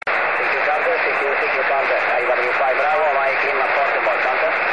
ベランダからポールを伸ばし、今回はZeppアンテナをぶら下げた。あまり期待せずにダイヤルを回すと、突如「ガン！」と入感するシグナルがあった。